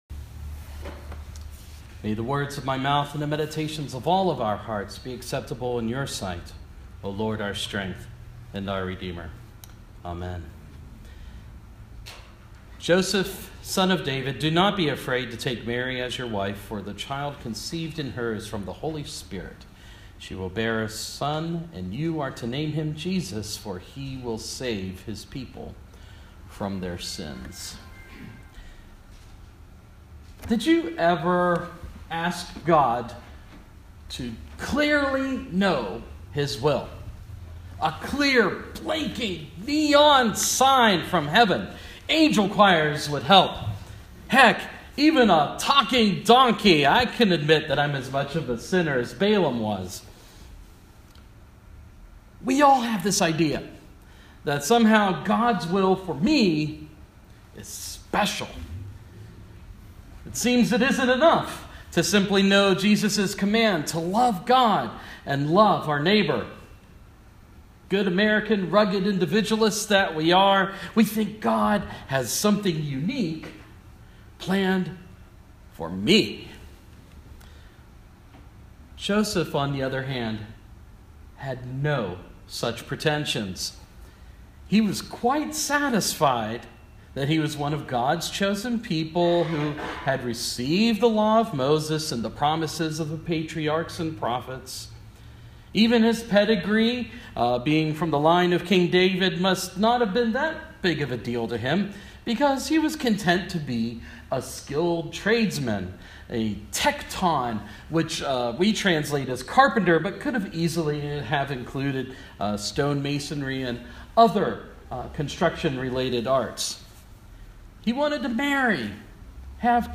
Our local ecumenical ministerium in Somerset holds weekly services on Wednesdays at noon during the seasons of Advent and Lent, and I was at bat today. Using the Gospel lesson from Sunday, we looked at the life of Joseph and how we might know God's will (hint: the Great Commandment).